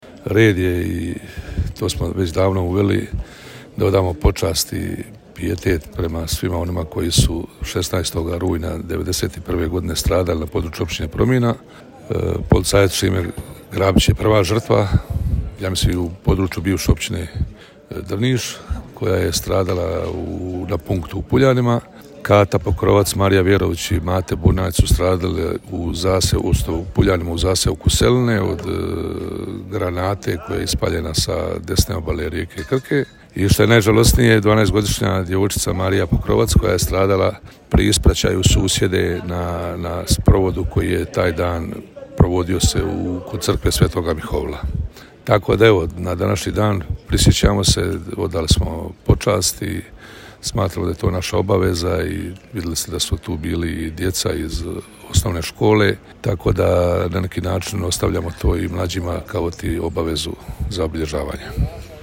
U organizaciji Općine Promina jutros je u Puljanima i Oklaju odana počast prvim žrtvama u Domovinskom ratu.
Načelnik Općine Promina Tihomir Budanko:
Tihomir-Budanko-1609-DNEVNIK.mp3